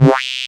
Sweep Up (JW3).wav